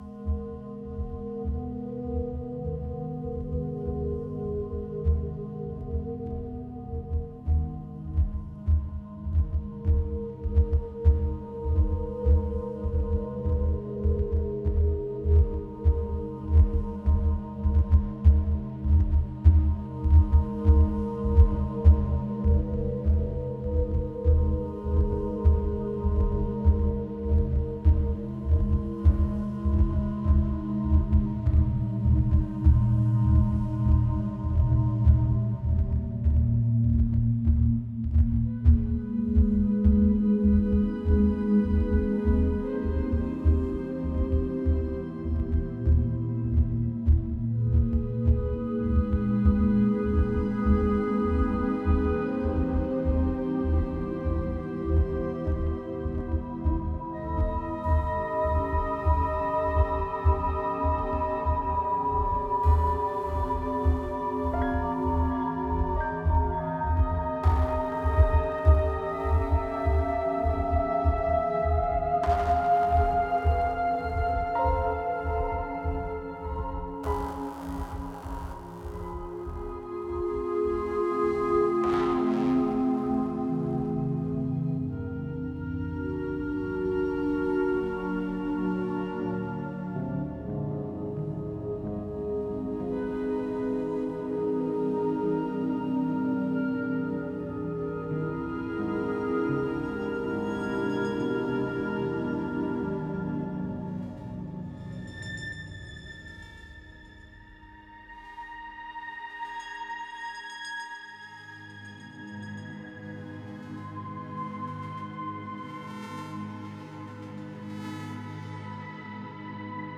Ambience 6 - Exploring the Ark - (Loop).wav